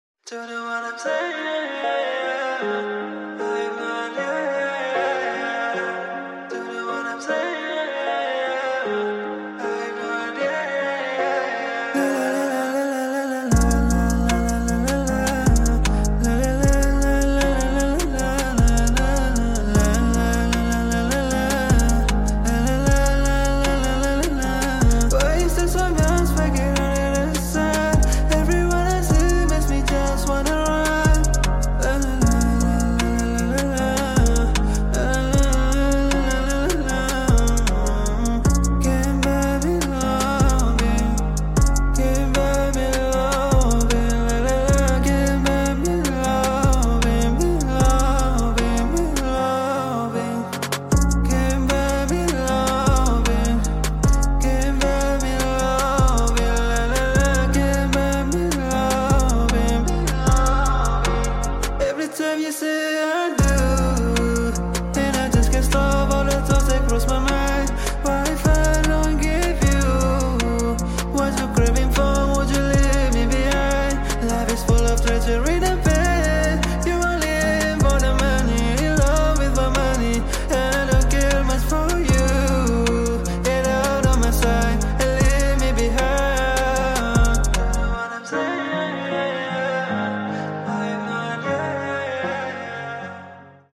⚡Rain falling. Wipers on. Throttle